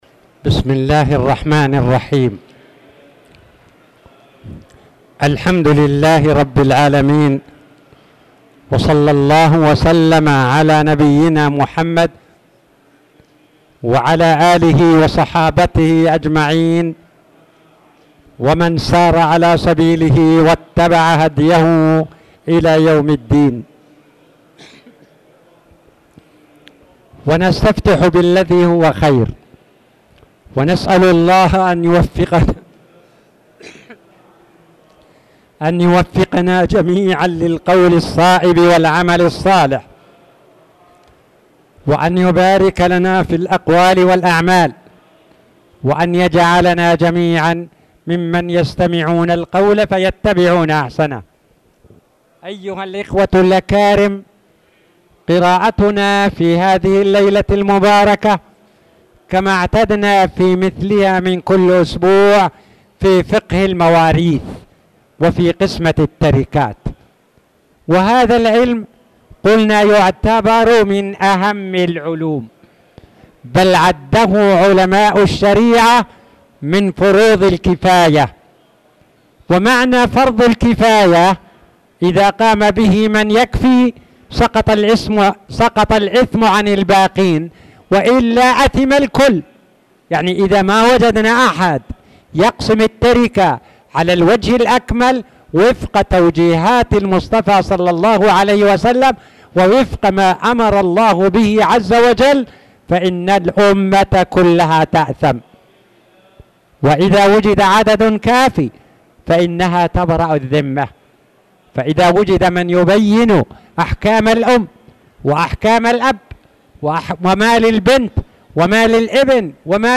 تاريخ النشر ٥ ذو القعدة ١٤٣٧ هـ المكان: المسجد الحرام الشيخ